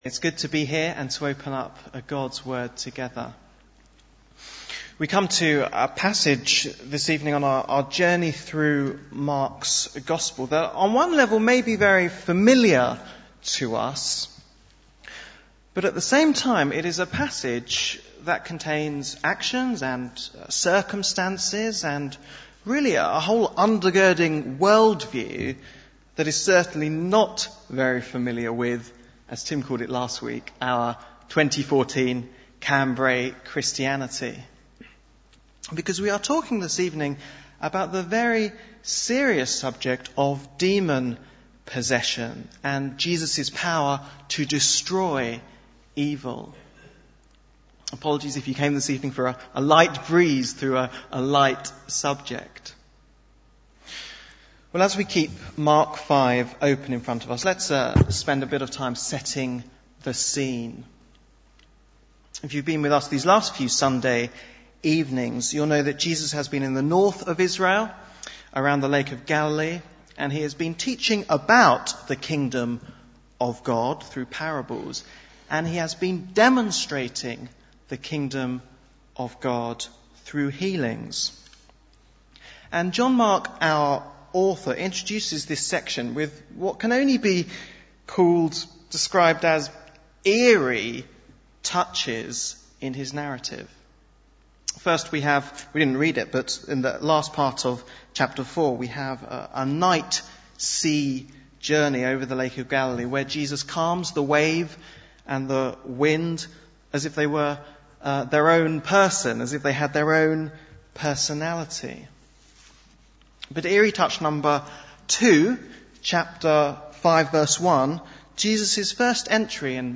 Bible Text: Mark 5:1-20 | Preacher